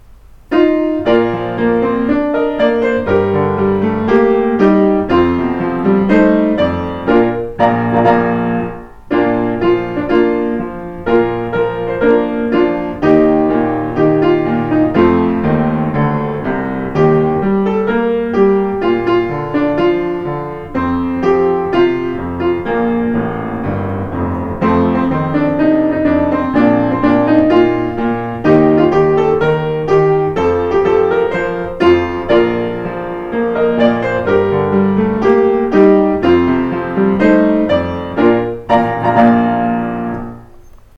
さらに、話の流れからピアノもひいてもらえることになりました。